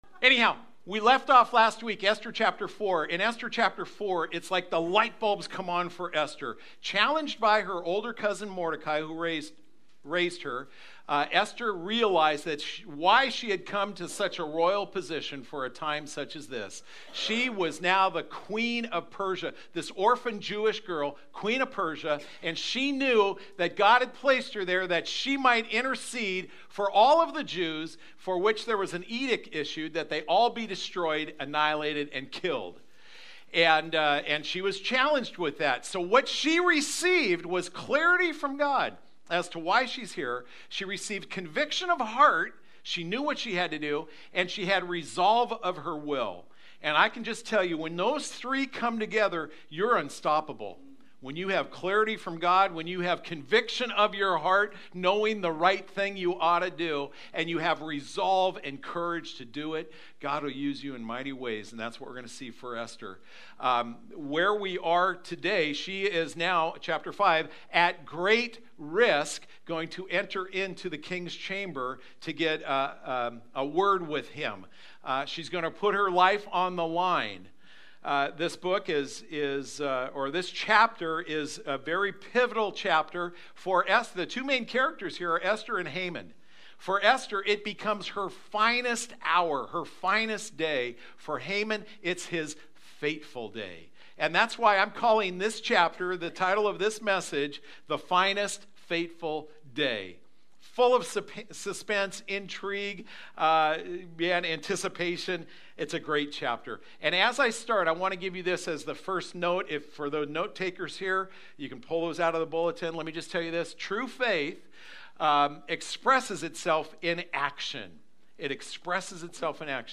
Sunday-Sermon-3-17-19.mp3